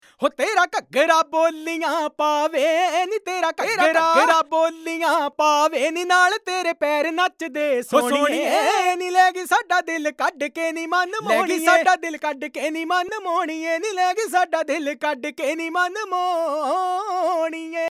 Key E Bpm 84